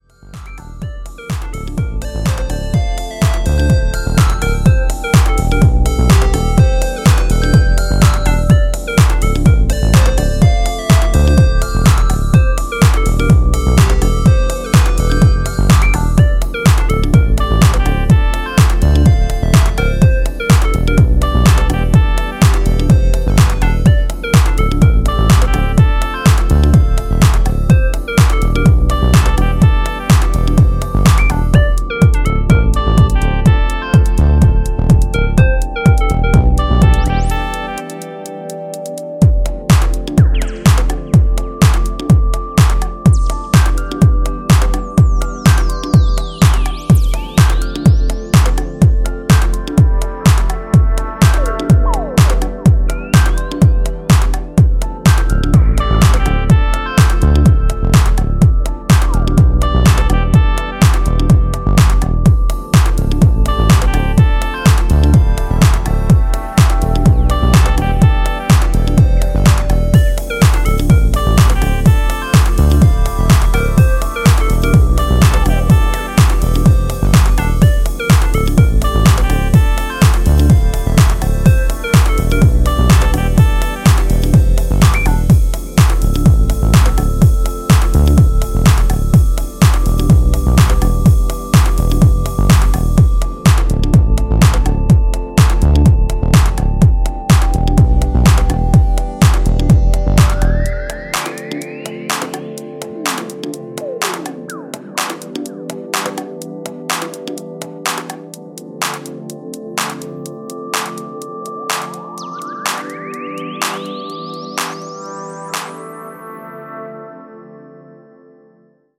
ムーディーなディープ・ハウスを展開していくB面